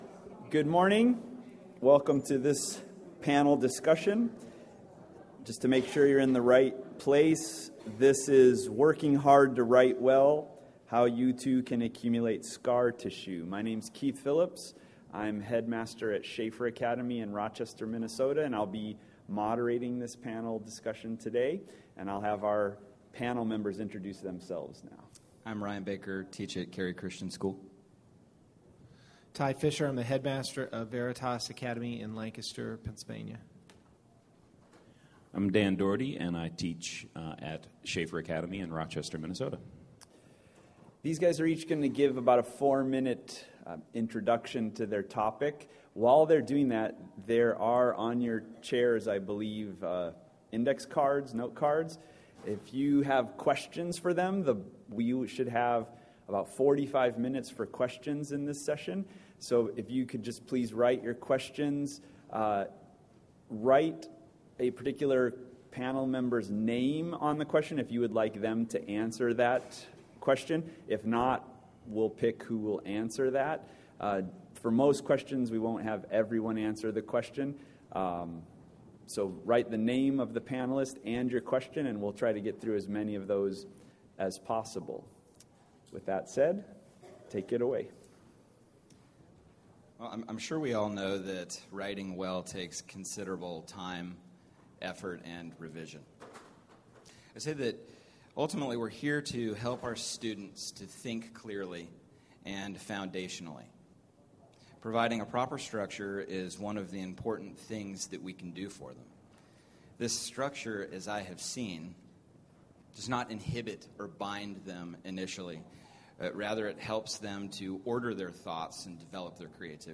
Working Hard to Write Well – Panel Discussion | ACCS Member Resource Center
The Association of Classical & Christian Schools presents Repairing the Ruins, the ACCS annual conference, copyright ACCS.
Panel Working Hard to Write Well.mp3